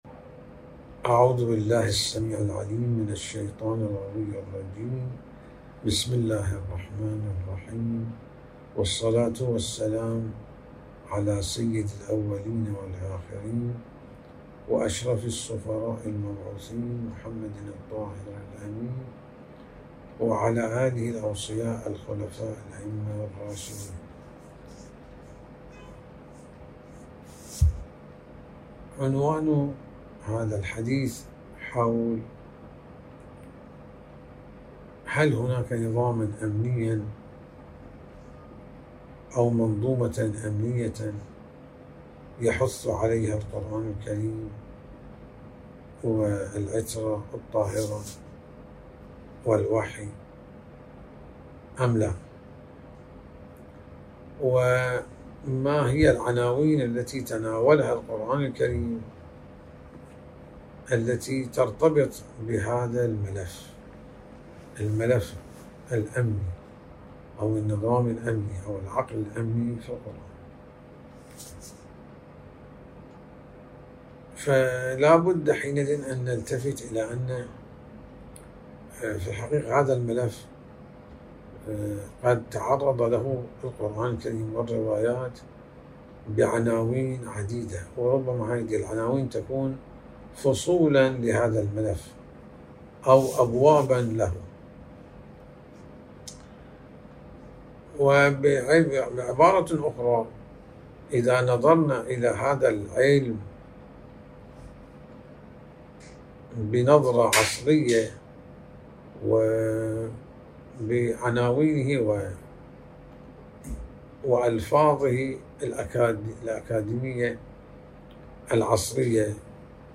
وشرح ذلك، الأستاذ والمدرس في الحوزة العلمية بالنجف الأشرف، سماحة المرجع الديني آية الله الشيخ محمد السند في حوار خاص له مع وكالة الأنباء القرآنية الدولية(إکنا).وفيما يلي نصّ المقابلة: